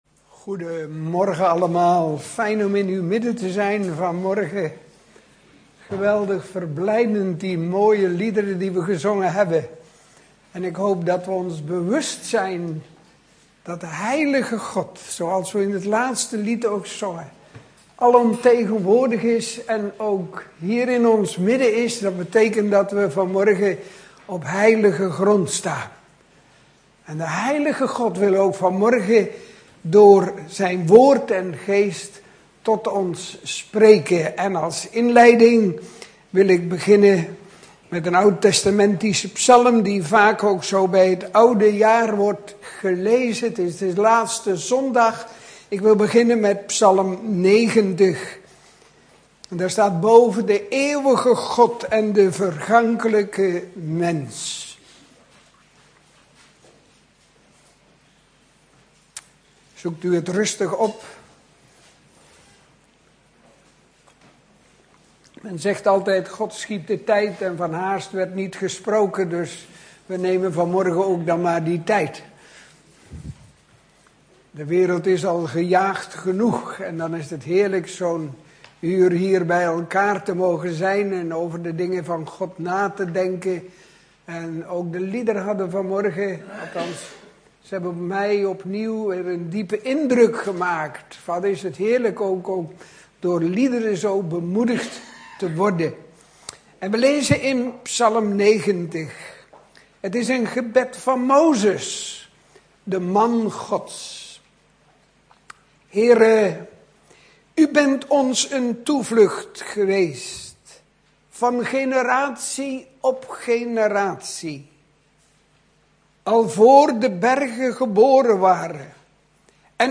In de preek aangehaalde bijbelteksten (Statenvertaling) Psalmen 90 1 Een gebed van Mozes